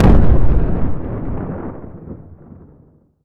explosion_deep_low_1.wav